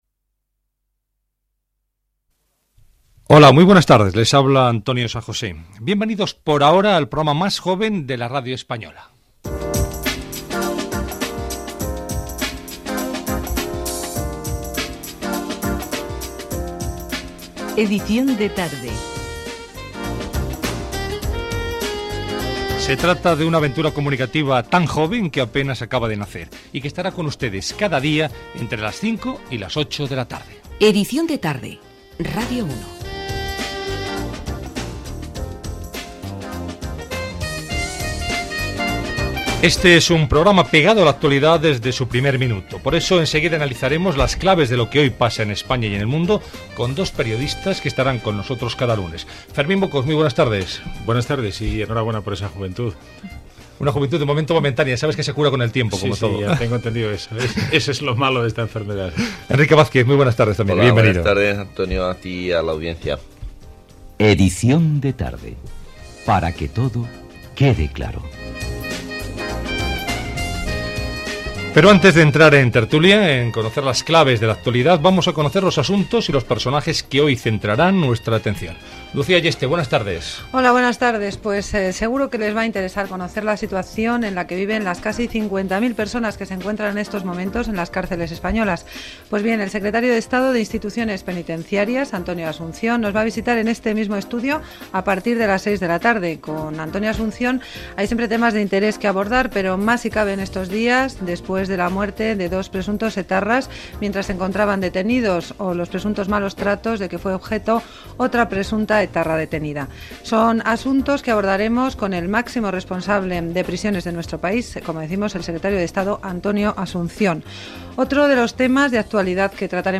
Salutació, indicatiu del programa, presentació del primer programa, sumari de continguts, cançó, indicatiu, hora, tertúlia d'actualitat